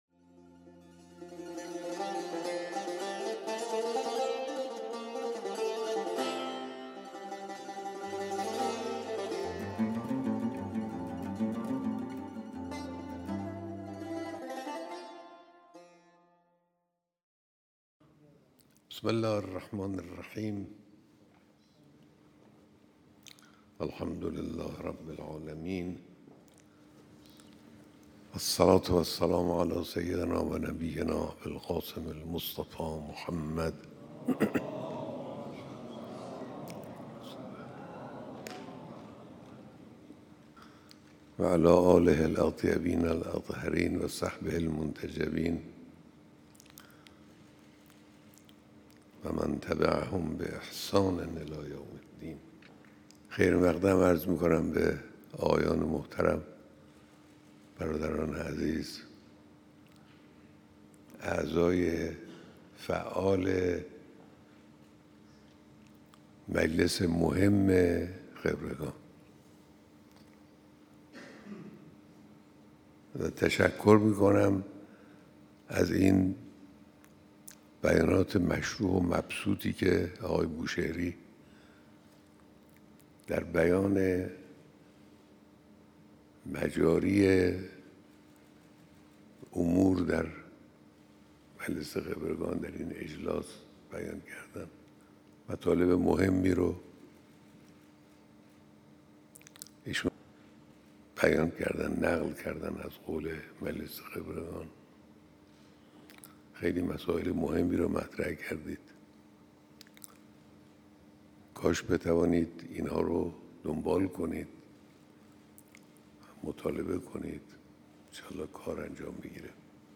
بیانات در دیدار رئیس و اعضای مجلس خبرگان رهبری